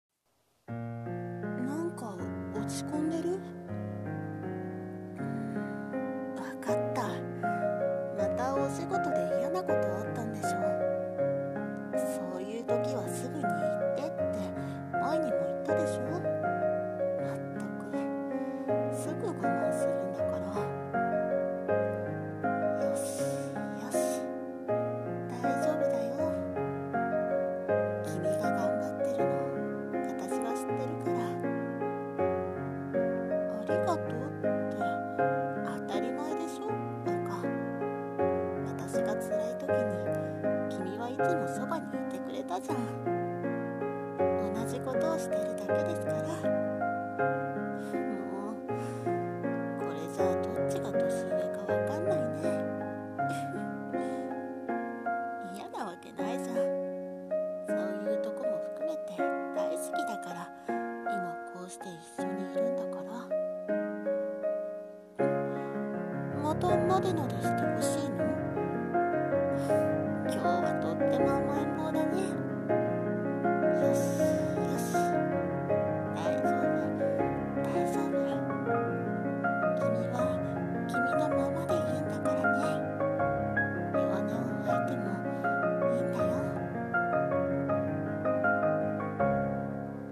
声劇[大丈夫]【１人声劇】